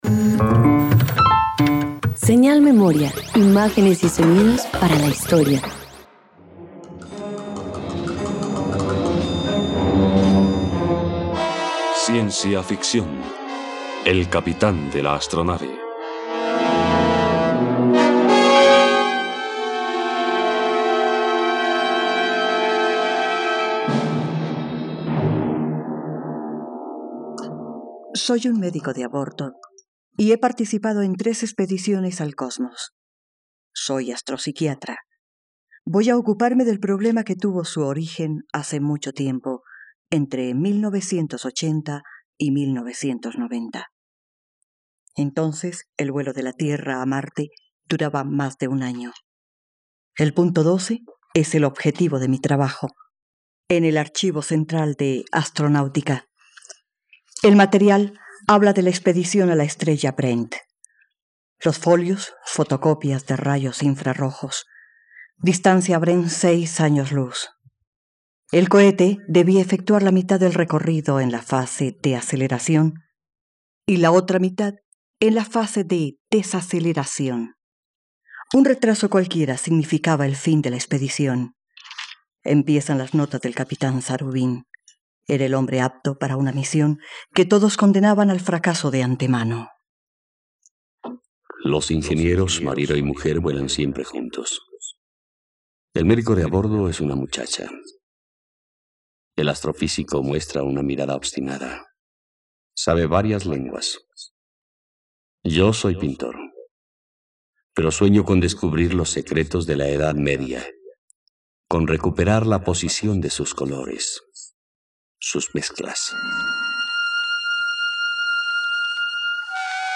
El capitán de la astronave - Radioteatro dominical | RTVCPlay
..Radioteatro. Escucha la adaptación para radio de la obra ‘El capitán de la astronave’, de la escritora rusa Valentina Zuravleva, en versión radiofónica libre de Gonzalo Vera Quintana.